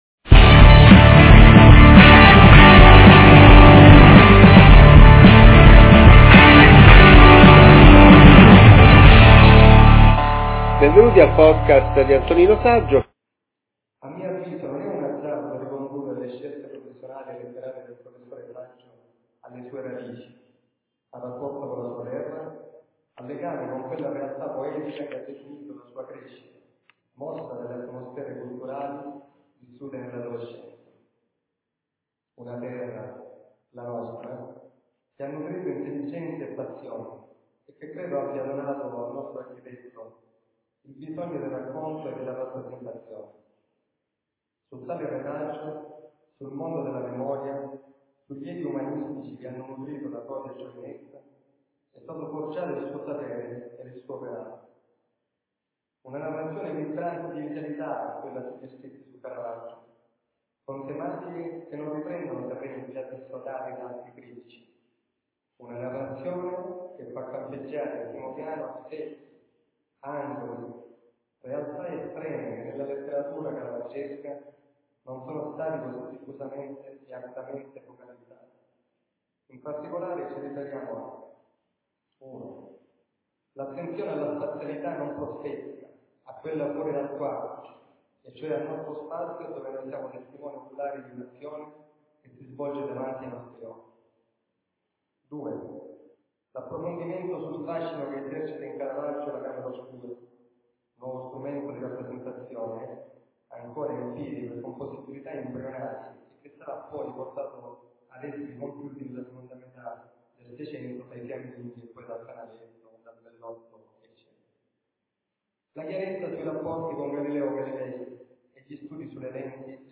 Lectio Magistralis
Chiesa di Santa Maria Alemanna, Messina, 6 settembre 2022 ore 18.00